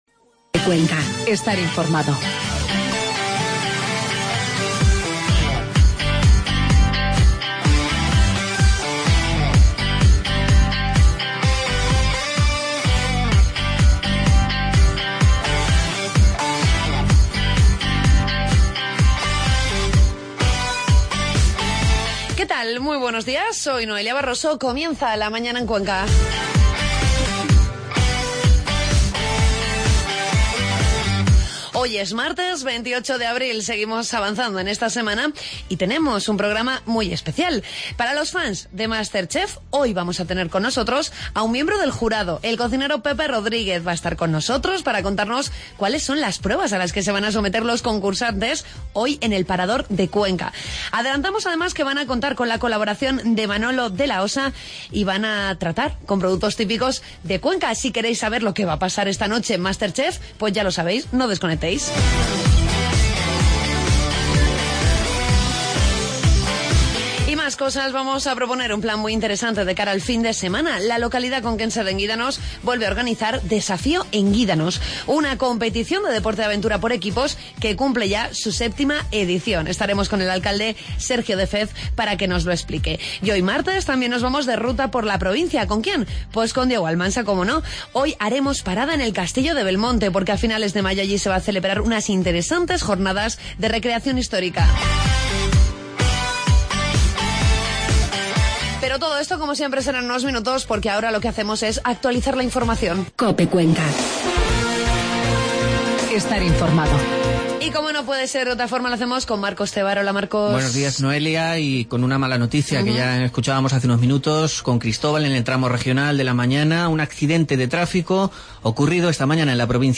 Entrevistamos a uno de los miembros del jurado de Masterchef, Pepe Rodríguez, ya que el programa de esta noche se desarrolla en la capital conquense. Hablamos de 'Desafío Enguídanos' una actividad que se celebra este fin de semana en la localidad.